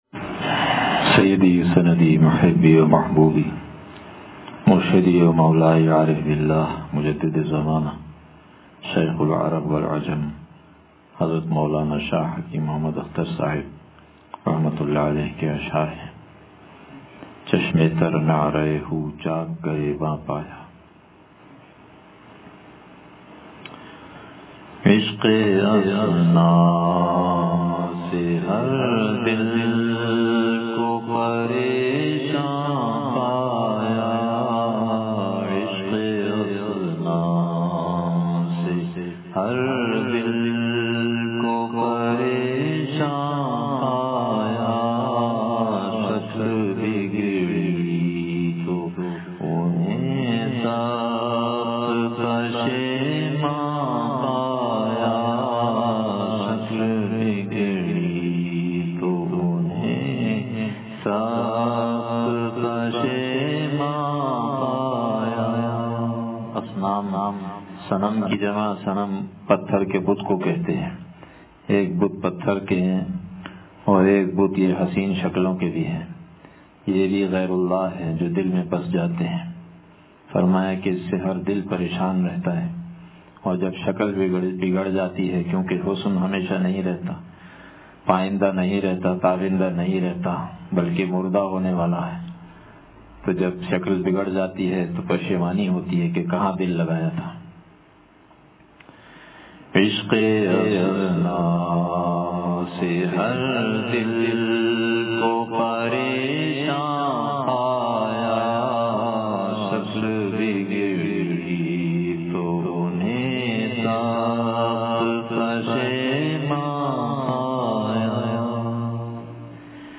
چشمِ تر نعرہ ھو چاک گریباں پایا – بیان